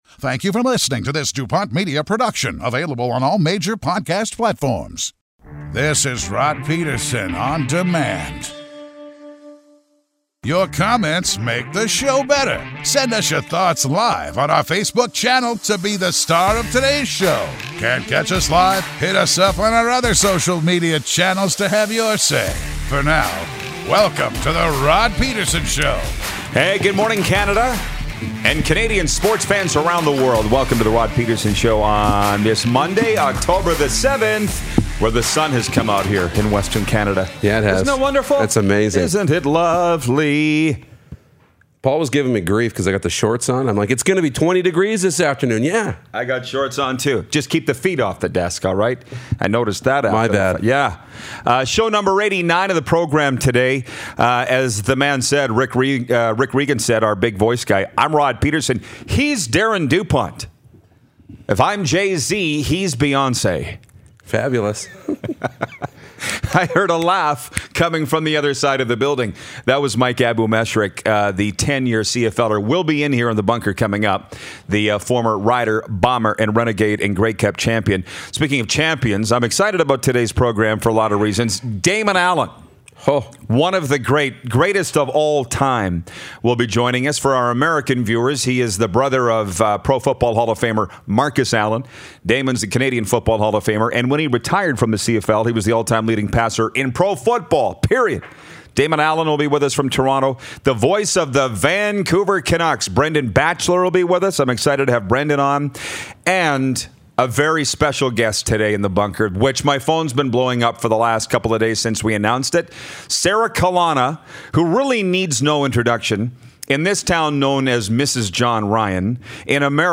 Join us for some invigorating sports talk!
Hall of Famer Damon Allen calls in!